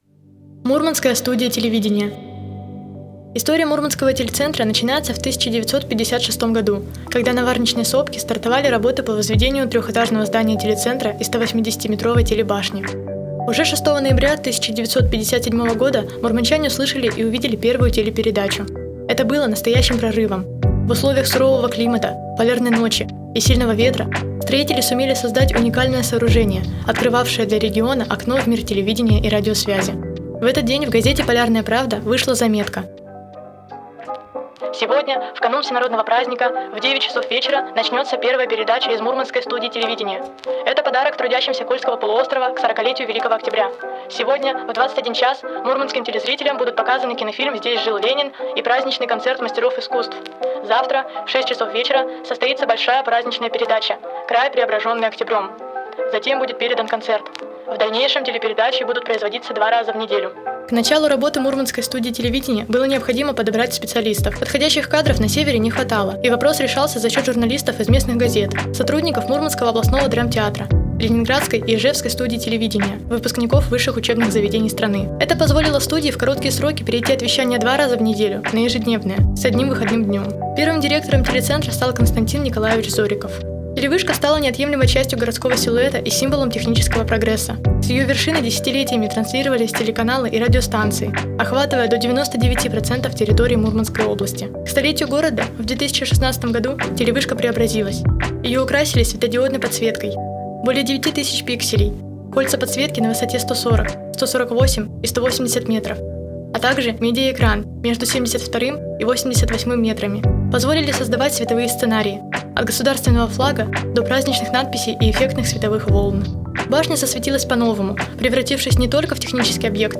«Мурманская телевышка»: аудиоэкскурсия